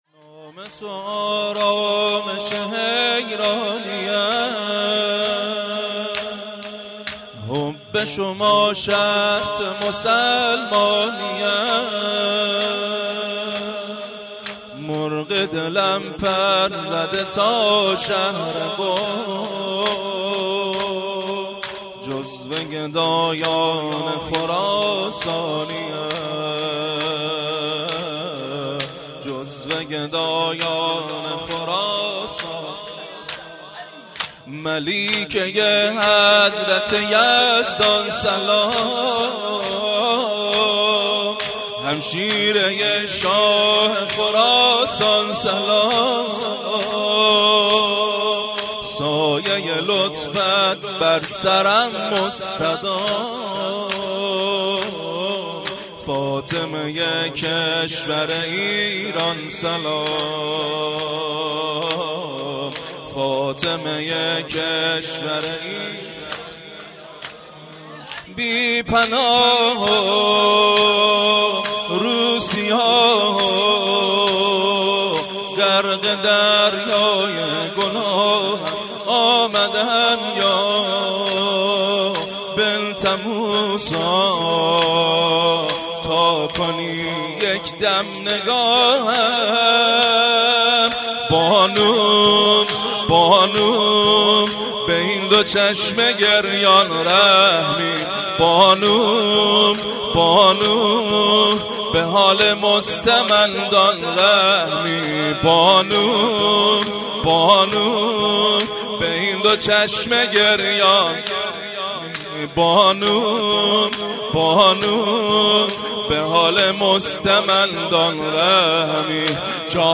زمینه - نام تو آرامش حیرانی ام (زیبا - پیشنهاد دانلود)
وفات حضرت فاطمه معصومه(س)جمعه8 دیماه1396